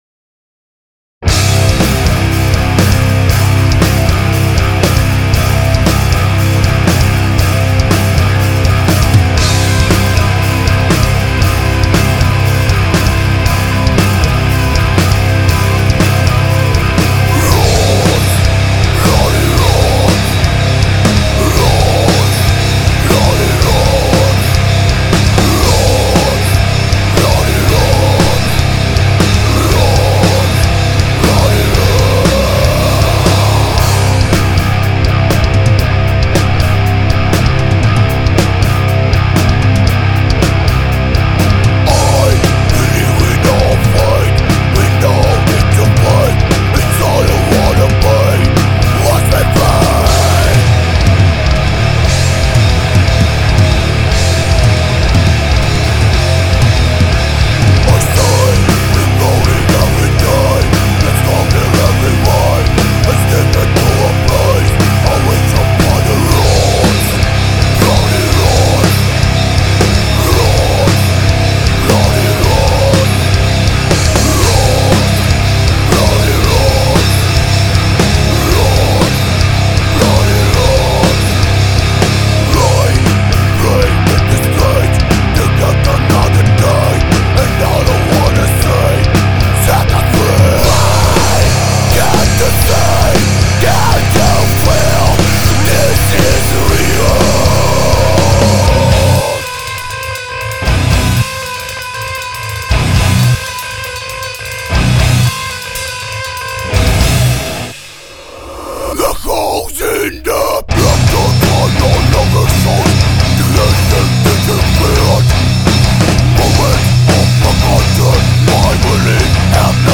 gitár